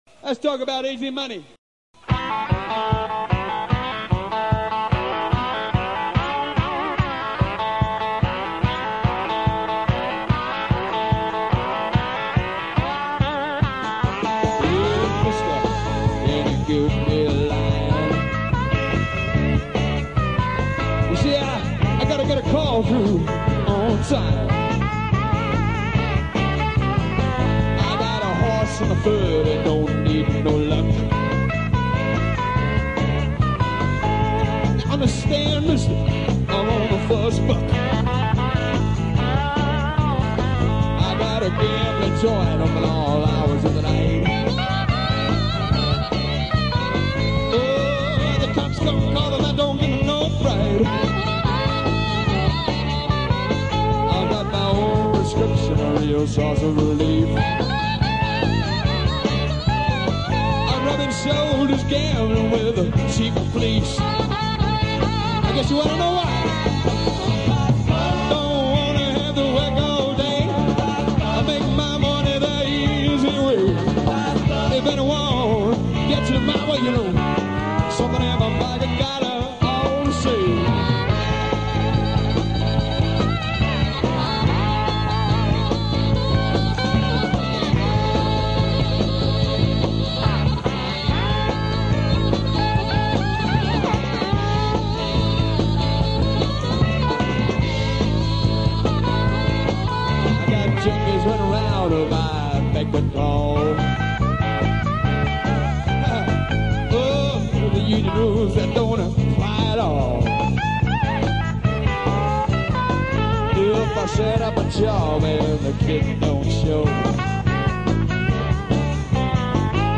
This gig was a live recording in Melbourne.
vocals
harp
drums
bass and vocals
guitars
The room was great, with natural reverb.